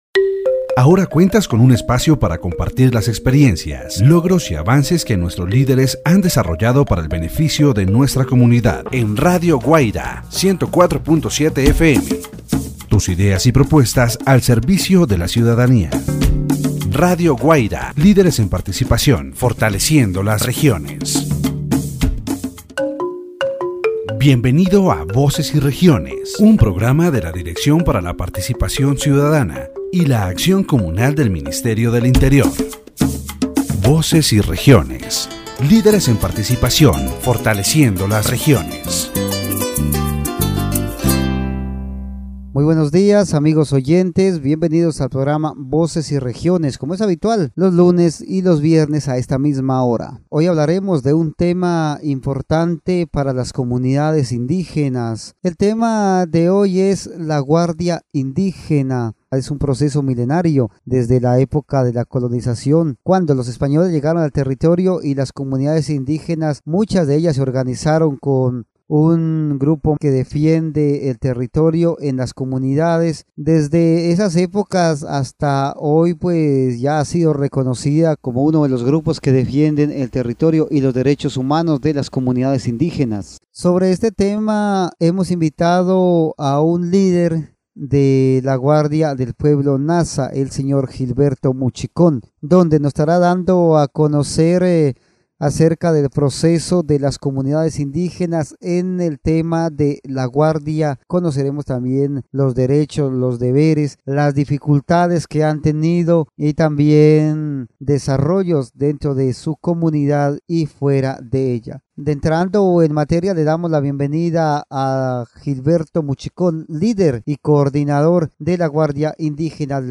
The radio program "Voces y Regiones" on Radio Guaida 104.7 FM, directed by the Ministry of the Interior, addresses issues relevant to indigenous communities. In this episode, the importance of the Indigenous Guard is discussed, an ancient group in charge of protecting the territory and human rights of indigenous communities.